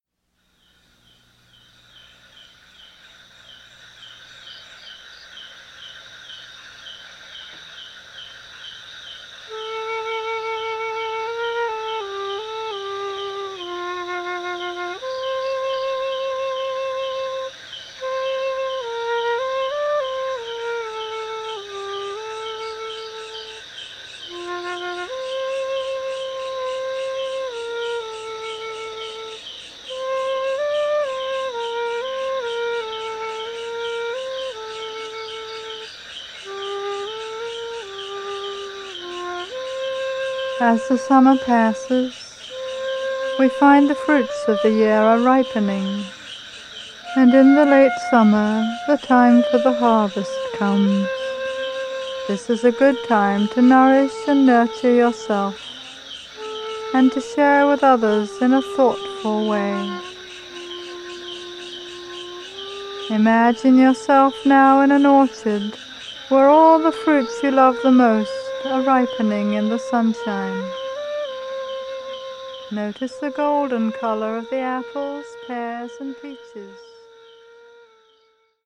Seasons for Healing: Summer (Guided Meditation)